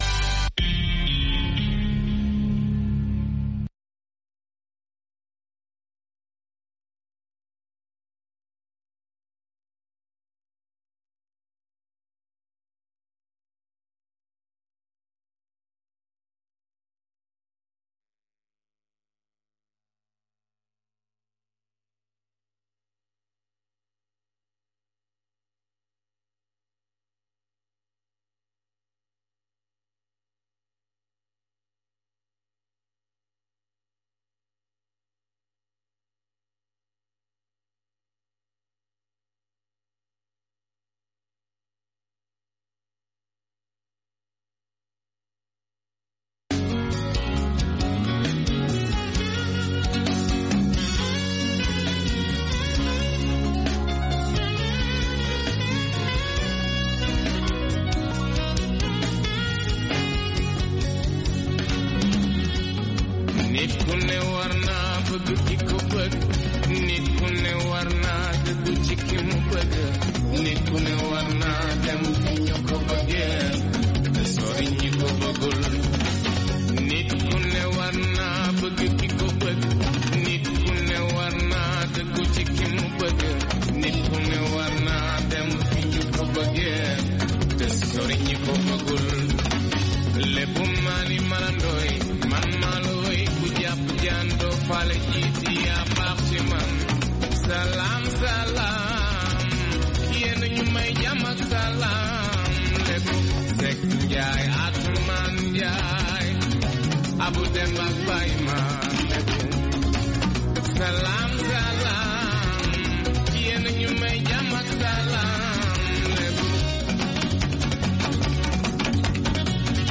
Blues and Jazz Program Contactez nous sur facebook